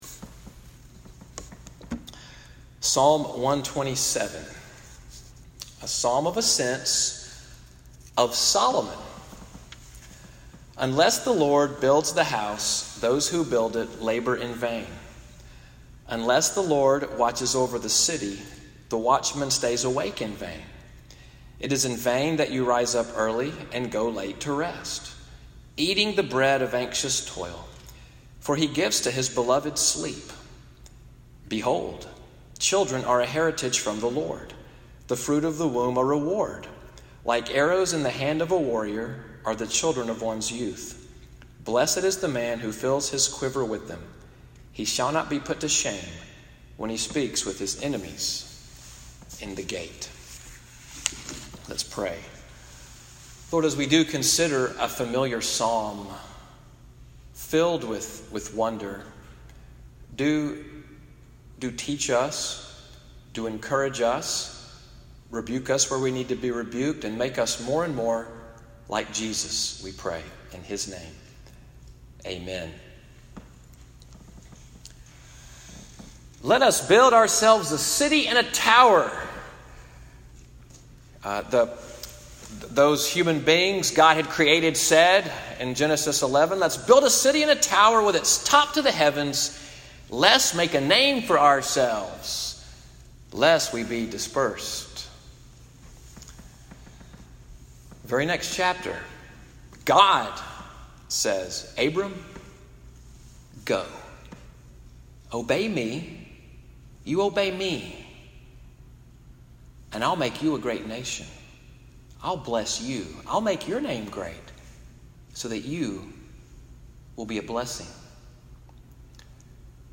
Evening Worship at NCPC-Selma, audio from the sermon, “The Builder’s Psalm,” (22:47) June 24, 2018.